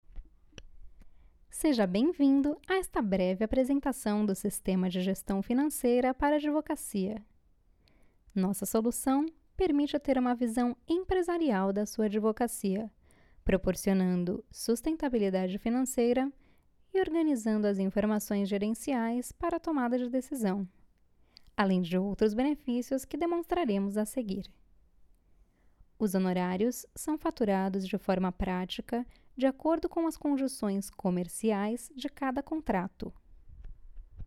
Sweet voice, good for sales, serious.
Sprechprobe: eLearning (Muttersprache):